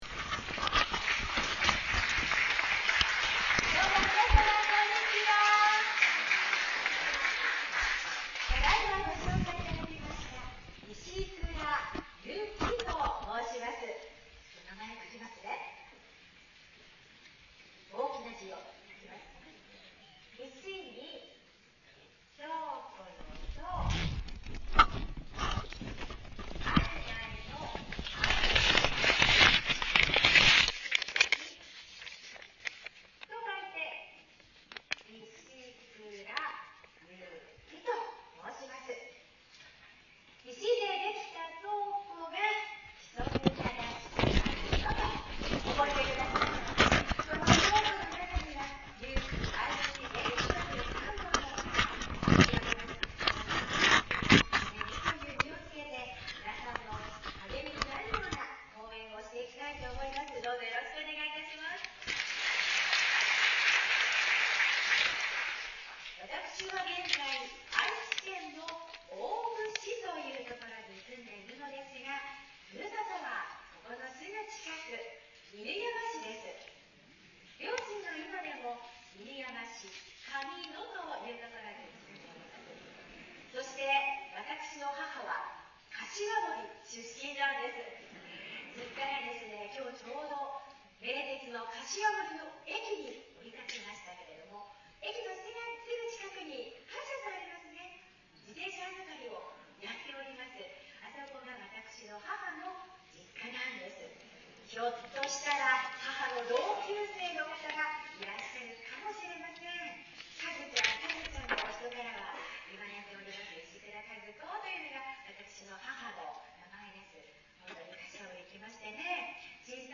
老人会の公演等
講師は几帳面な方で予め用意されたレジメに沿って よく通る声と慣れた話し振りで120分間 一気に公演された。人と気持よくつきあうための4箇条とは： 第一 感じよく肯定的に話す。